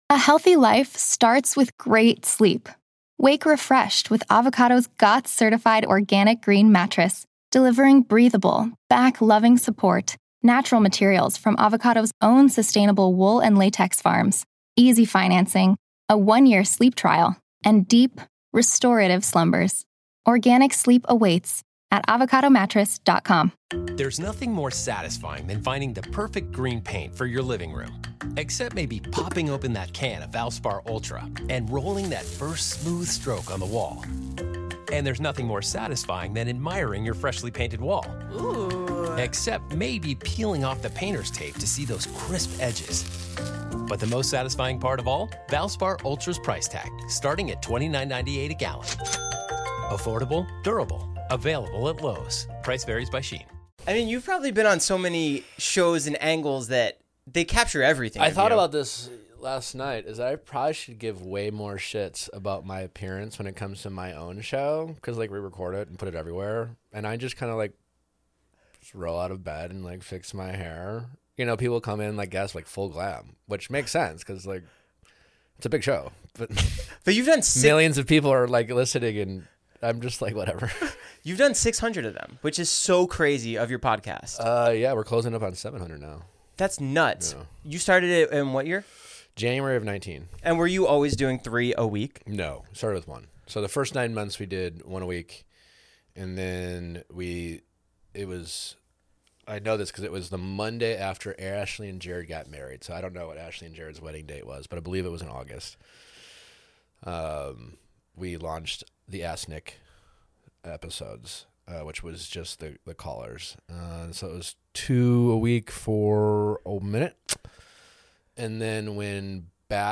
Nick Viall of the Viall Files podcast, military training show SPECIAL FORCES, and The Bachelor / Bachelor in Paradise joins Lightweights to discuss the new brutal show he's on. The extreme conditions and crazy challenges he has to go through, being one of the most well known people in the world, fighting Tom Sandoval, and more.